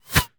pgs/Assets/Audio/Guns_Weapons/Bullets/bullet_flyby_fast_03.wav
bullet_flyby_fast_03.wav